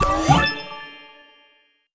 Collectibles_Items_Powerup
collect_item_12.wav